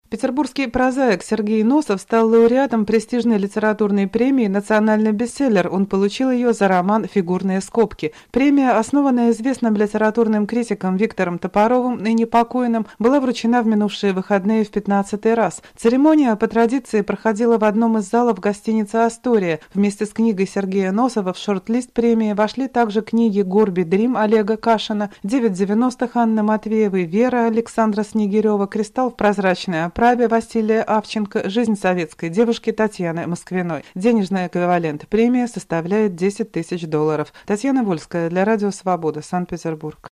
Рассказывает корреспондент